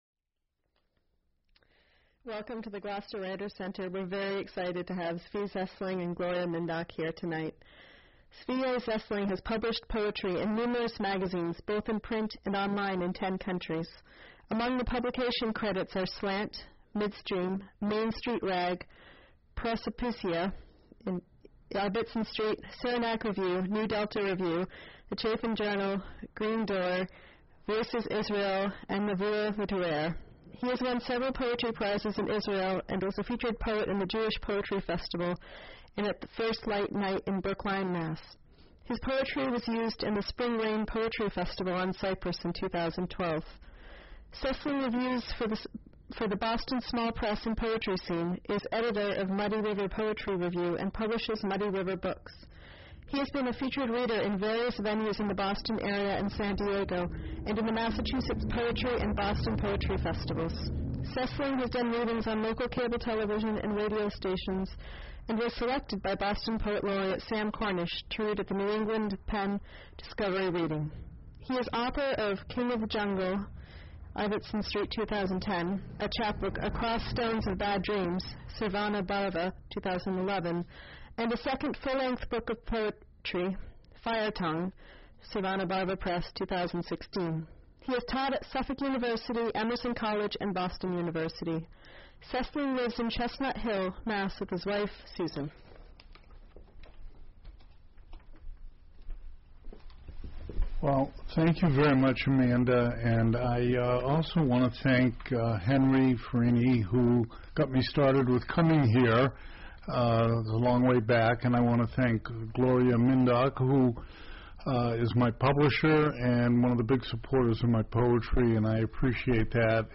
Poetry Reading - Gloucester Writers Center